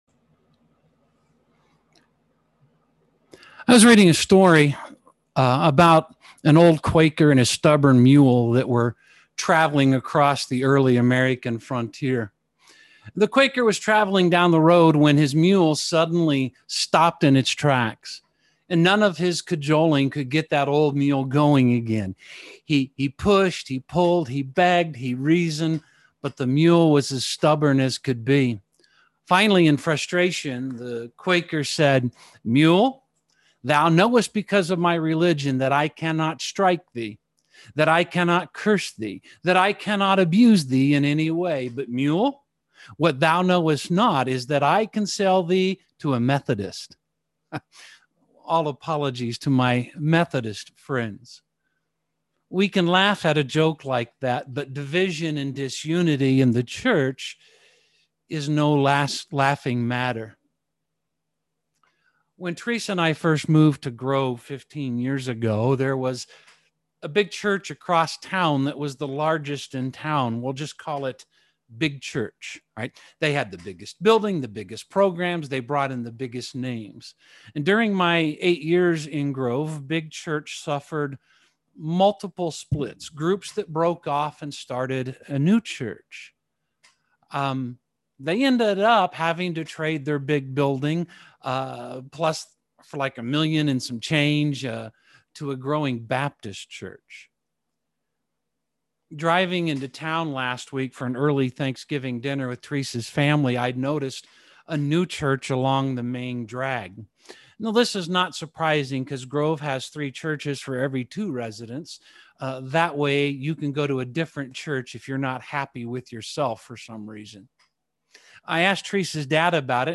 Unity Ephesians Core 52 Video Sermon Audio Sermon Save Audio Save PDF Did you know that Jesus prayed for you?